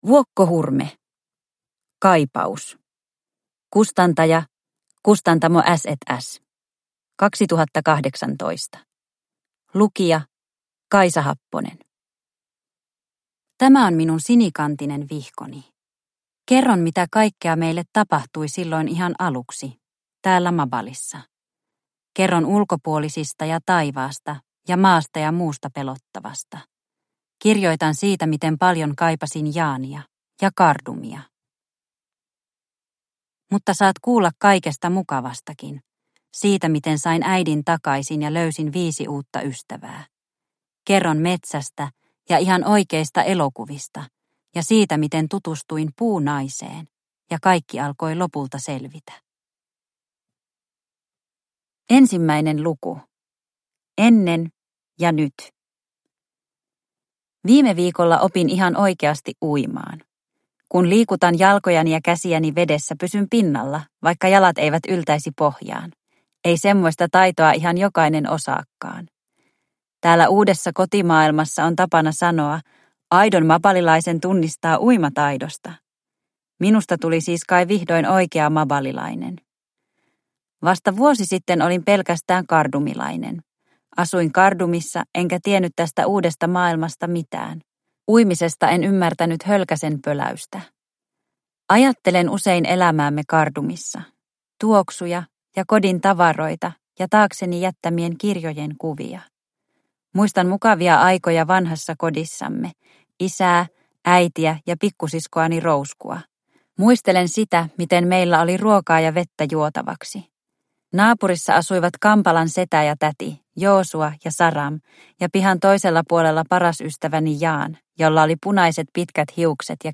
Kaipaus – Ljudbok – Laddas ner